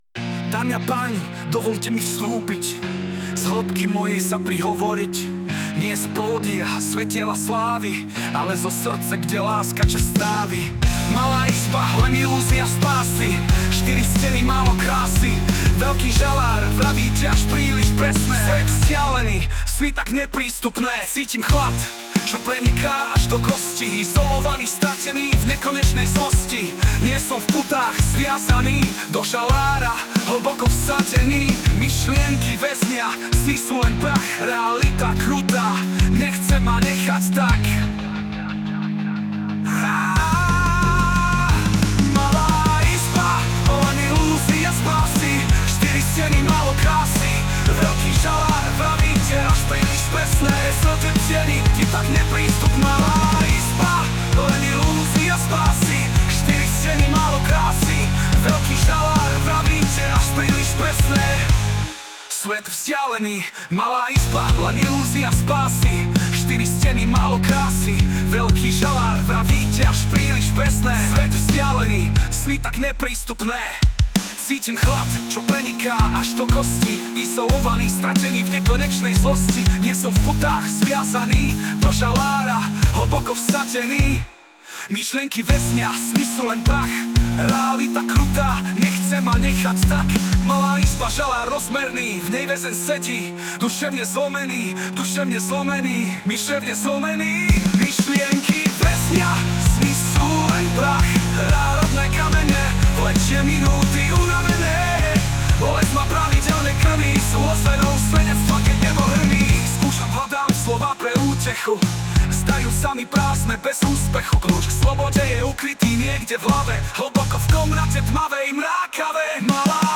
Text silnej a ta hudba mu pasuje, i to opakování motivů, super, fakt*